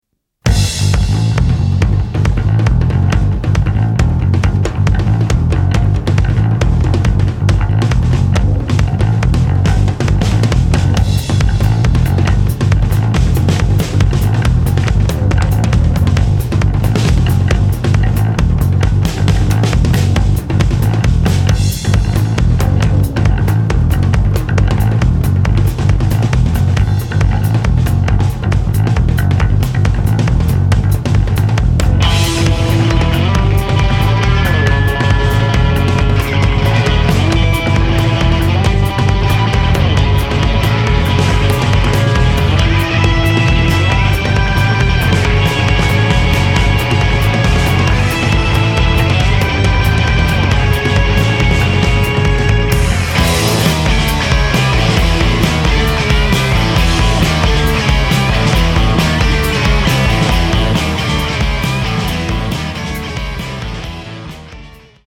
インストゥルメンタルの最も新しいカタチがここにある。
多才な４人のアンサンブルが強烈なグルーヴを産み、